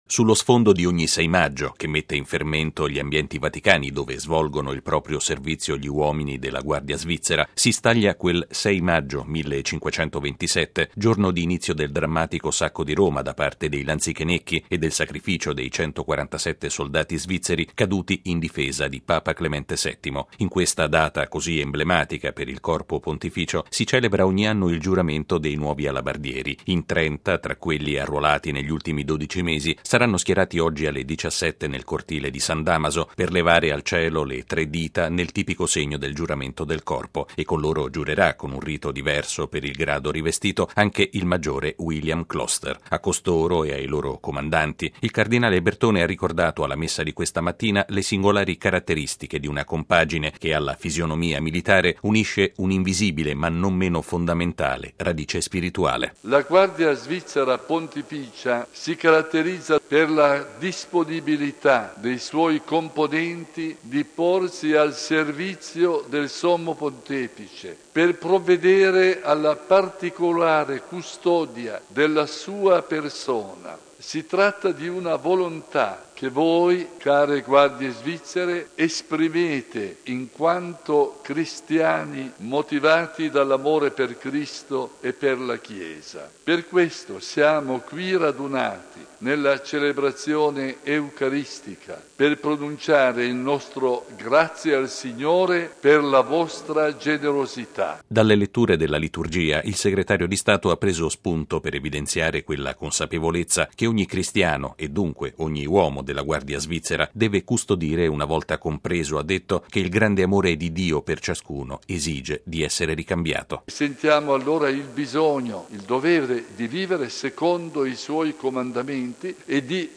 ◊   Un “servizio qualificato e apprezzato”, che esige una condotta di vita “irreprensibile”: questo significa svolgere il servizio come Guardia Svizzera. A ricordarlo ai 30 nuovi alabardieri che oggi pomeriggio presteranno giuramento nel Corpo pontificio è stato il cardinale segretario di Stato, Tarcisio Bertone, che questa mattina ha presieduto la Messa nella Basilica di San Pietro, alla presenza del presidente della Confederazione Elvetica, delle massime autorità del Corpo e dei familiari delle nuove reclute.